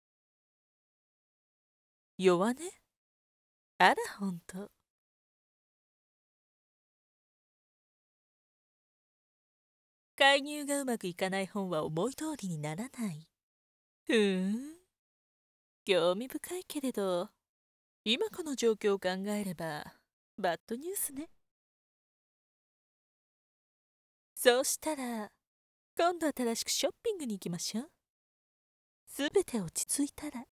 🏹 地下声劇